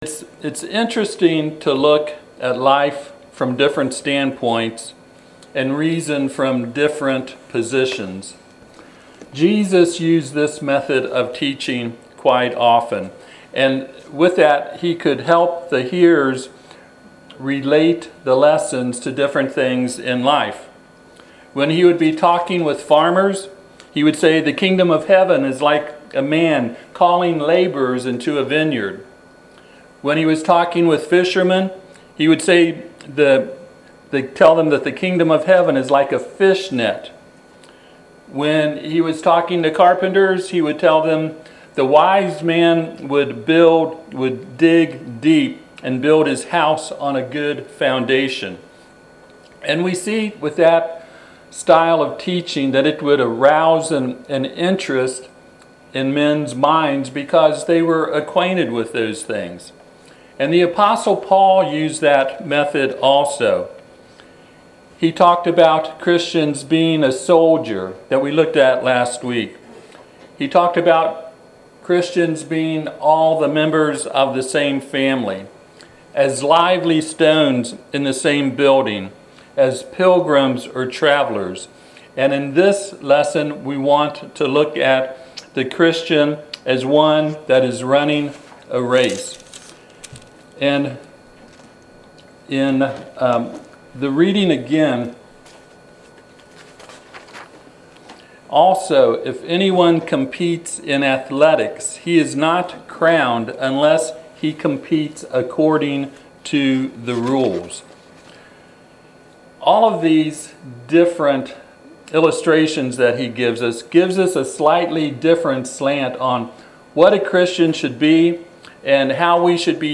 2 Timothy 2:5 Service Type: Sunday AM « Five things a Christian must do.